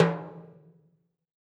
PTIMBALE L1R.wav